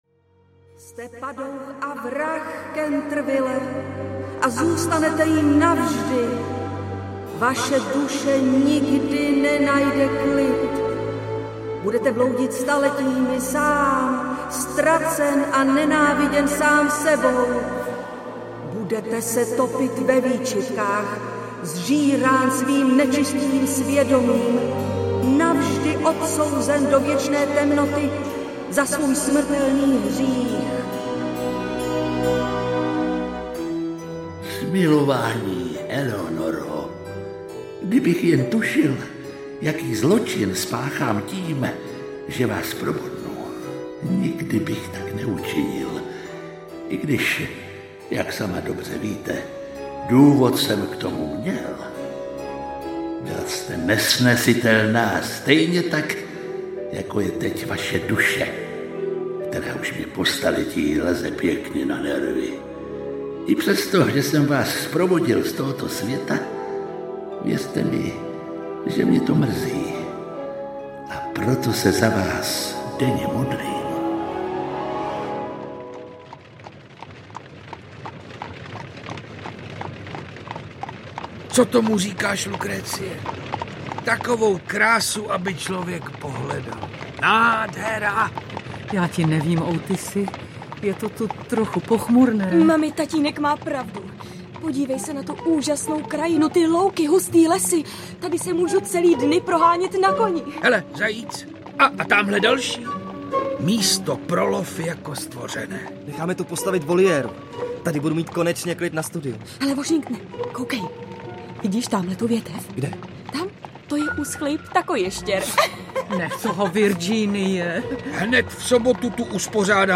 Strašidlo cantervillské audiokniha
Ukázka z knihy
Původní česká dramatizace na motivy povídky Oscara Wildea.